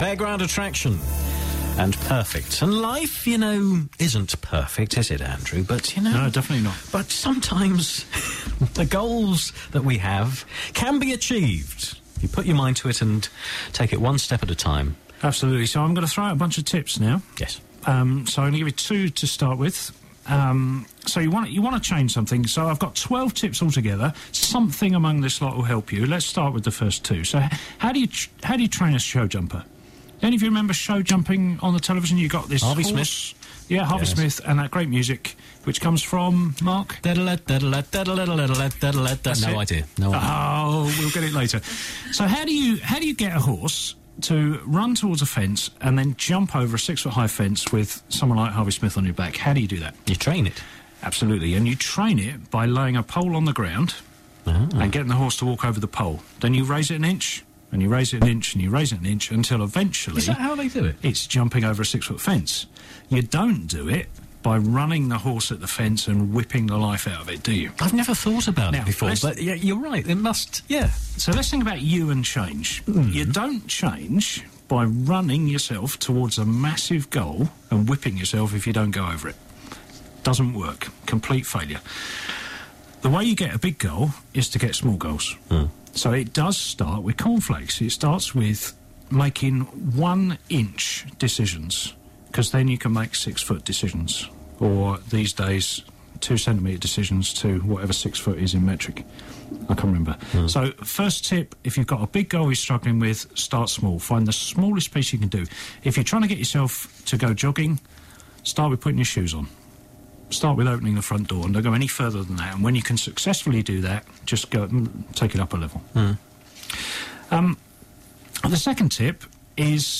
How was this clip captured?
All the best bits from my Sound Advice slot on BBC Essex 24 Jan 2012.